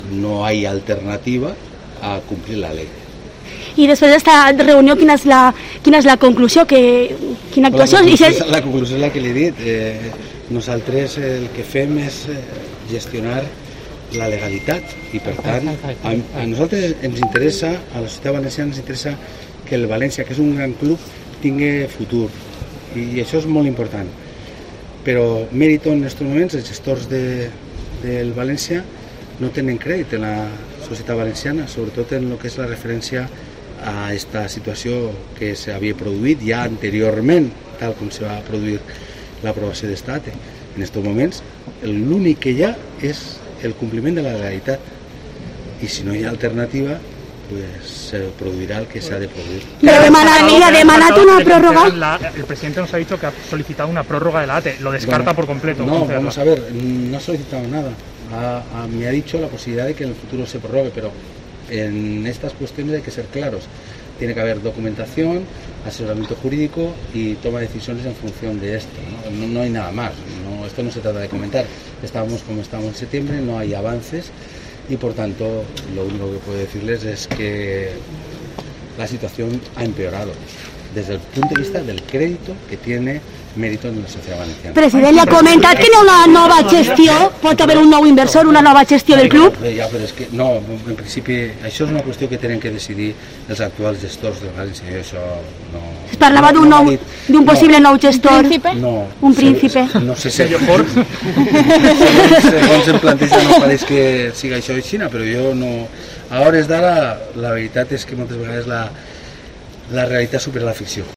AUDIO. Puig ha sido así de claro